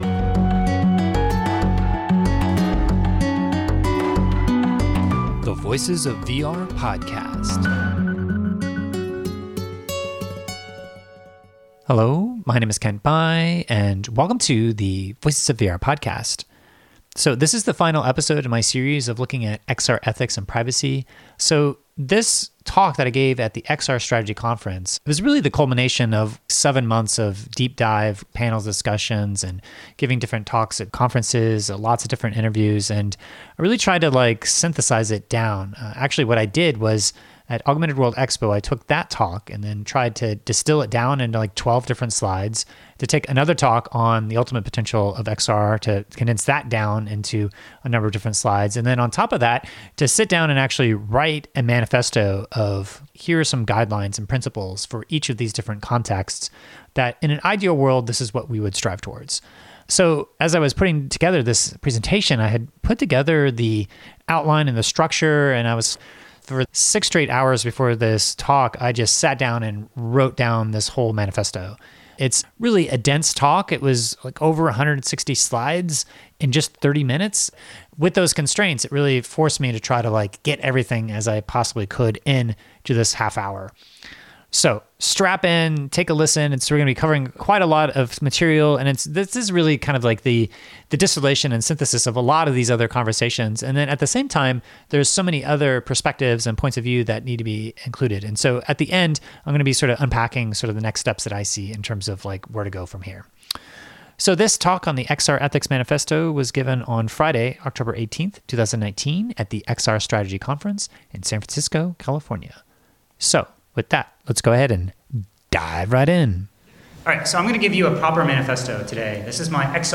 This is an XR Ethics Manifesto that I presented at Greenlight’s XR Strategy Conference on October 18, 2019. This is the culmination of seven focused months of panel discussions, interviews, and talks exploring many of the nuances of ethics and privacy in virtual and augmented reality.